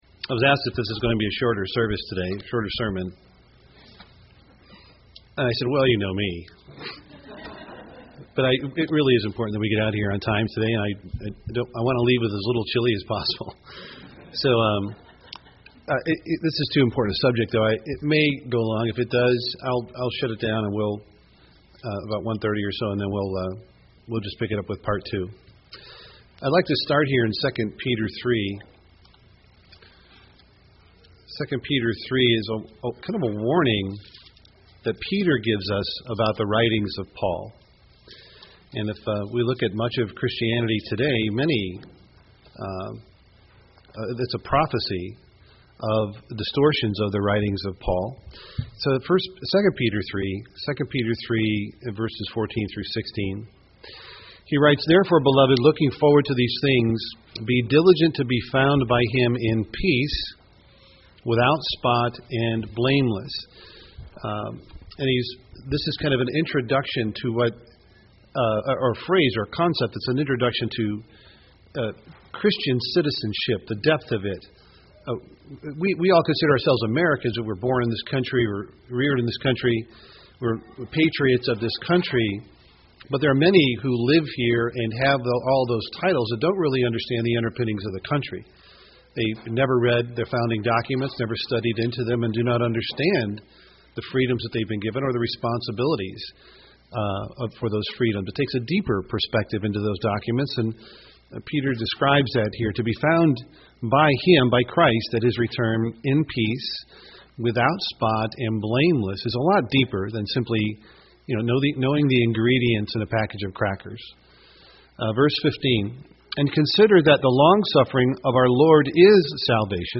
Given in Twin Cities, MN
UCG Sermon stumbling block Romans 14 Studying the bible?